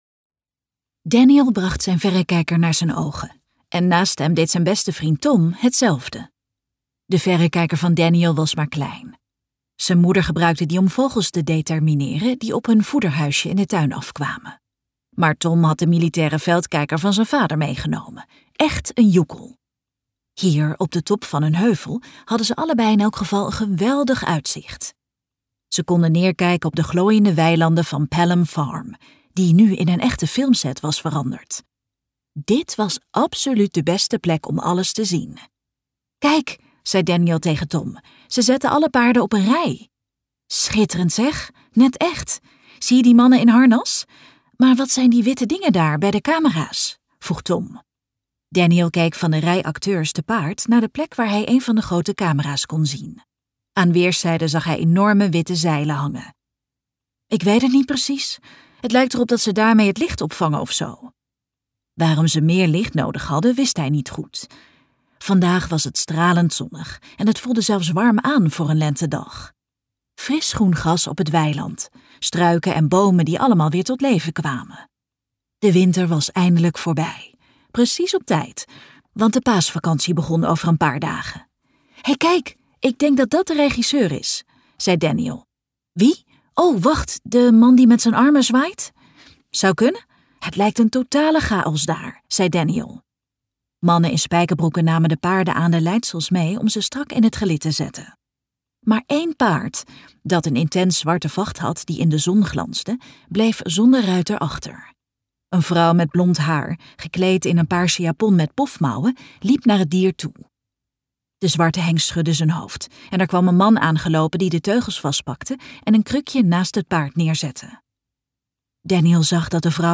Luisterboek